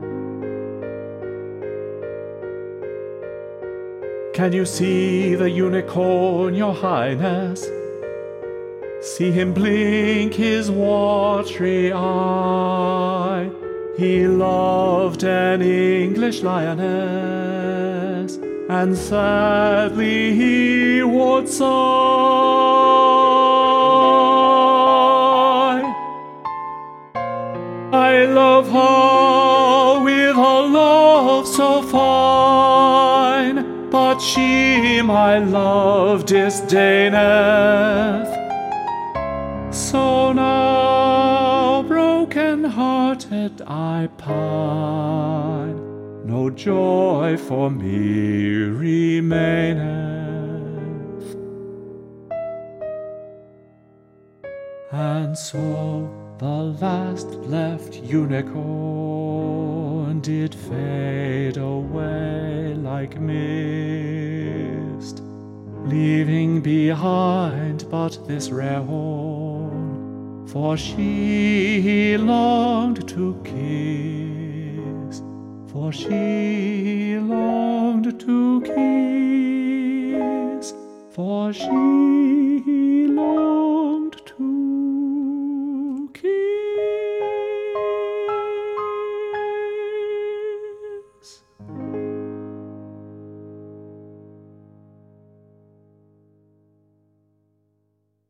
Hear the passage with Piper's part sung